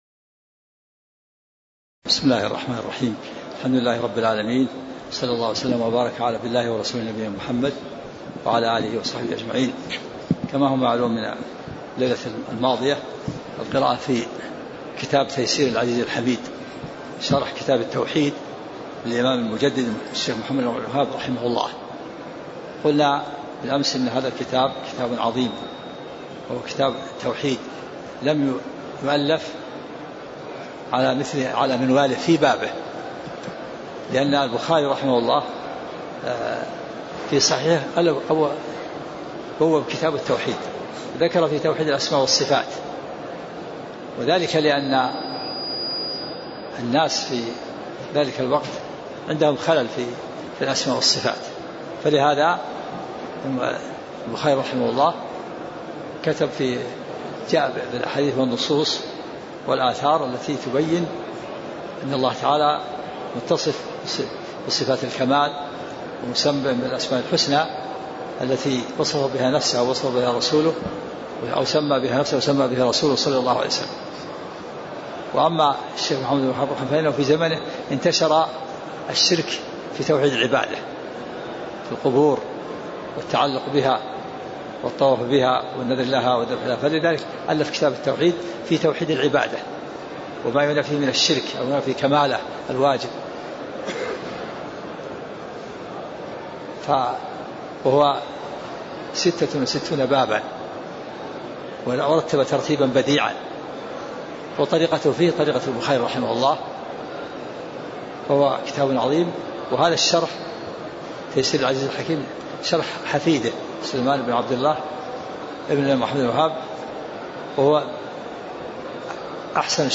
تاريخ النشر ٢٧ شعبان ١٤٣٦ هـ المكان: المسجد النبوي الشيخ: عبدالعزيز الراجحي عبدالعزيز الراجحي النصوص القرانية التي تدل على اتباع السنة وطاعة الله ورسوله (02)